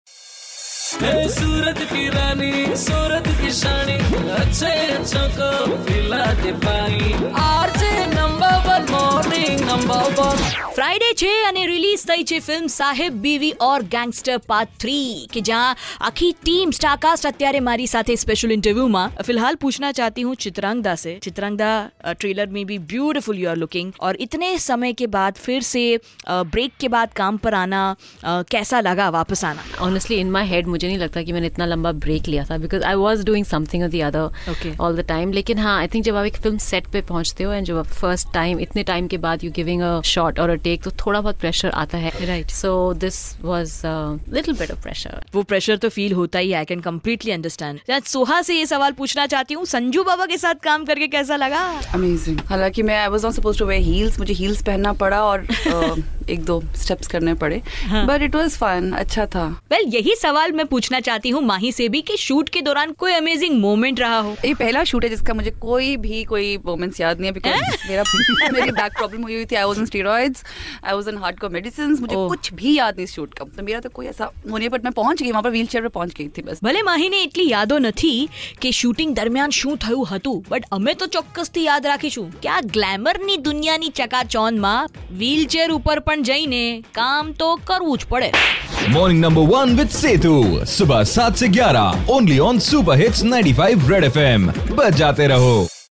SAHEB BIWI AUR GANGSTER 3 INTERVIEW
IN CONVERSATION WITH CAST OF MOVIE SAHEB BIWI AUR GANGSTER 3